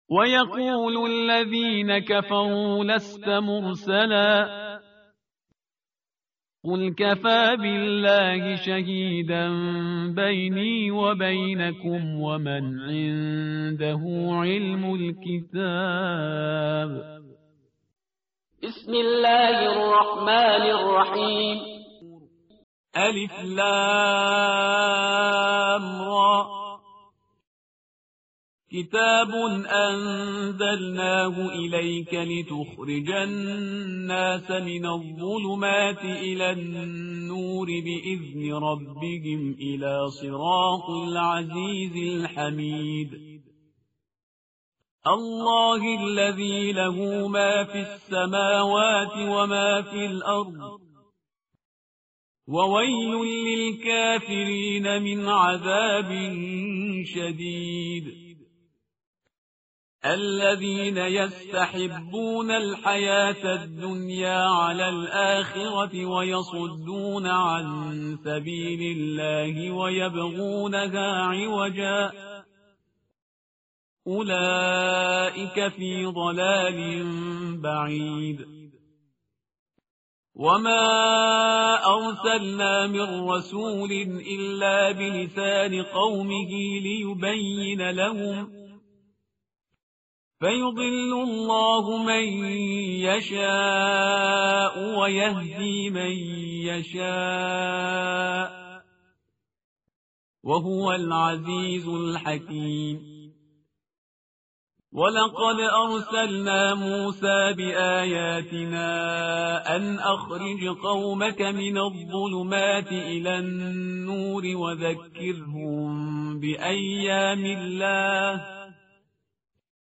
tartil_parhizgar_page_255.mp3